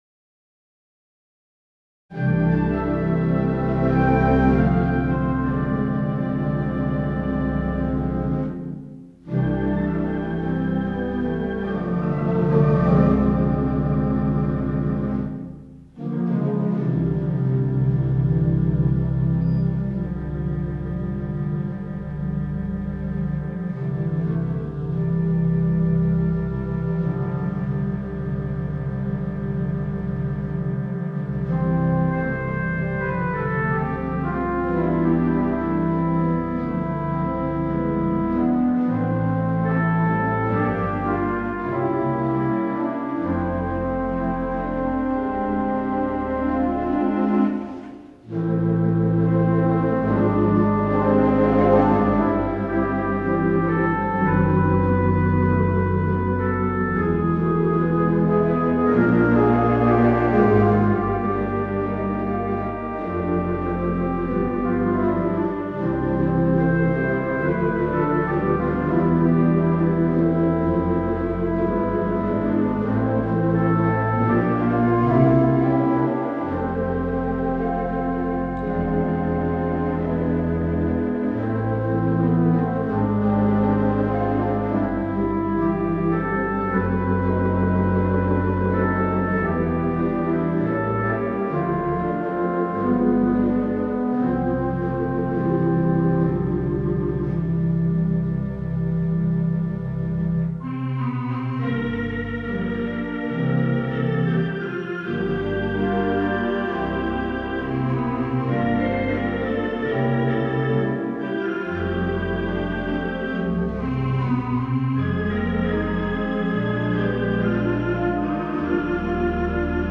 Christmas Music
Theatre Pipe Organ